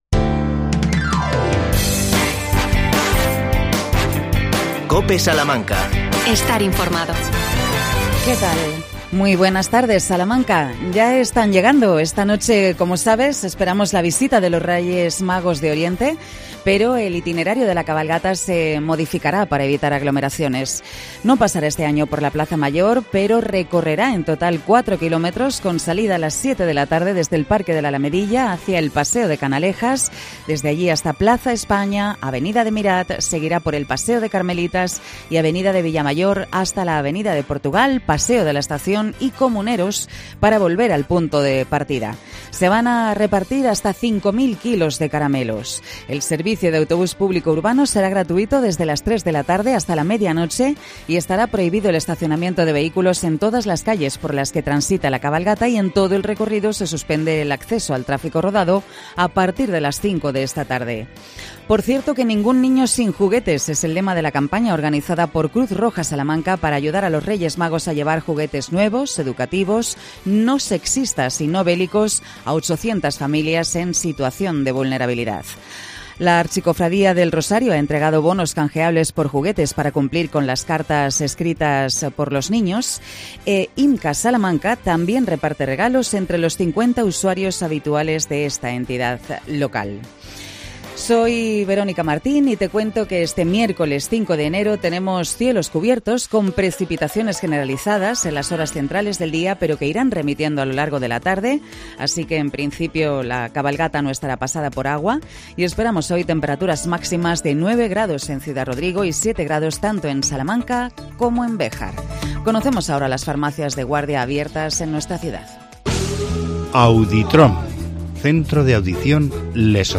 AUDIO: Cope Salamanca entrevista al Rey Mago Melchor antes de desfilar en la Cabalgata.